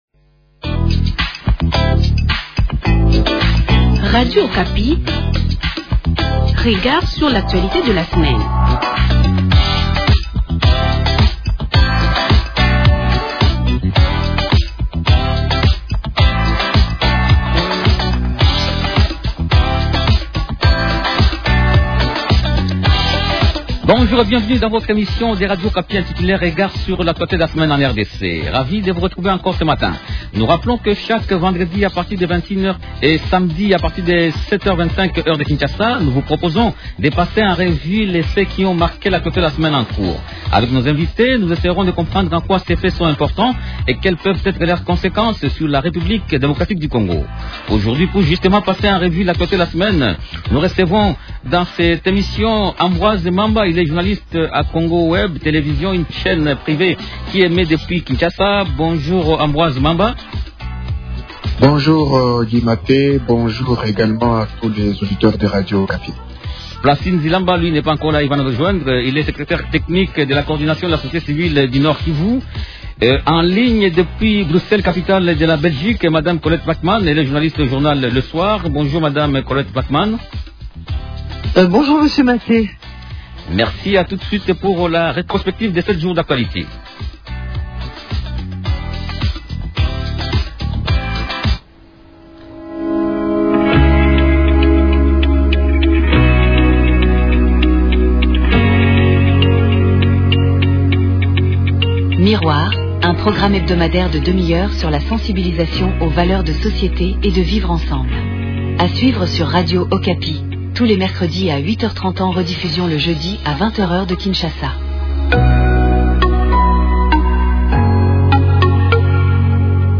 -Et en ligne depuis Bruxelles, capitale de la Belgique